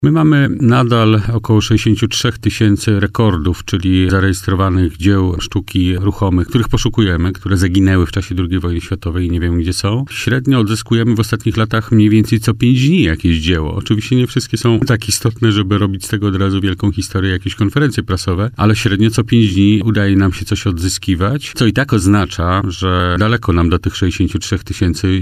Wiceminister resortu – Jarosław Sellin przyznał w poranku „Siódma9” na antenie Radia Warszawa, że na współpracy polsko-amerykańskiej w poszukiwaniu zaginionych dzieł sztuki korzystają obie strony.